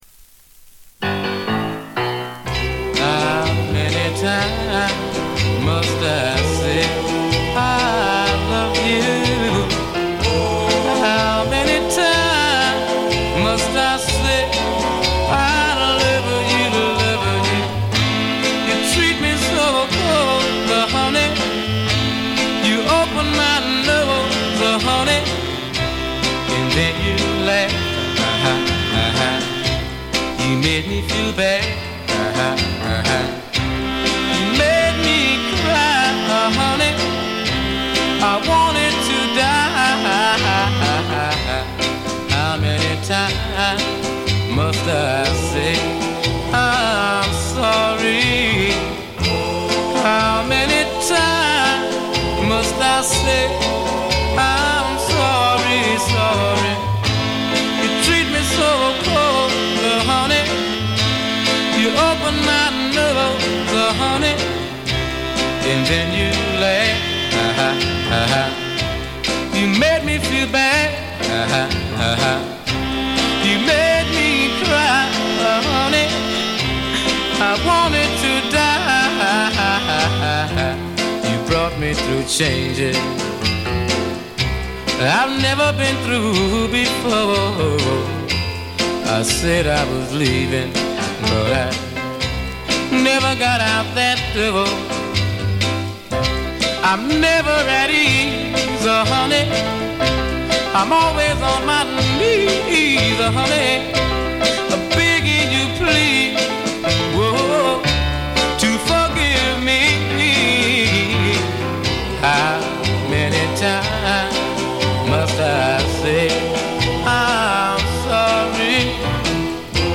静音部で微細なバックグラウンドノイズが聴かれる程度。
モノラル盤。
試聴曲は現品からの取り込み音源です。